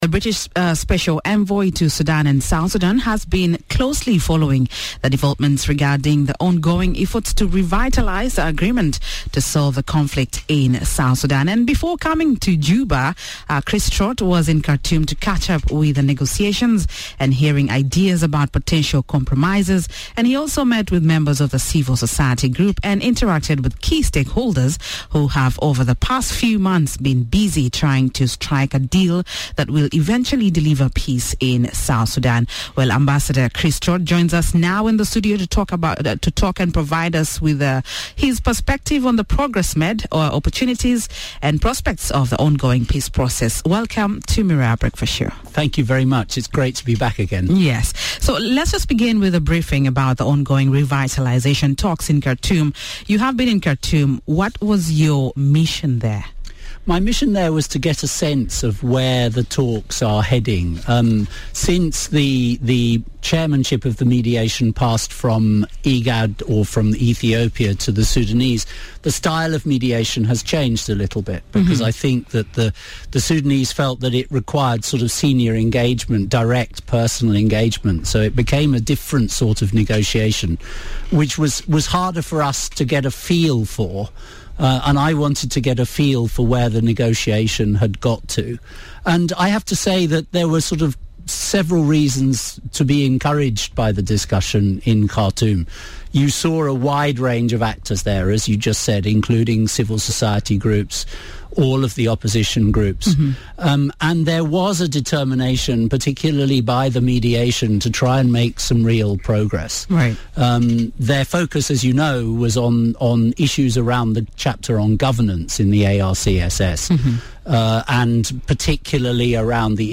Ambassador Chriss Trott was hosted on Miraya Breakfast Show today, to provide his perspective on the progress made, opportunities and prospects of the ongoing peace process.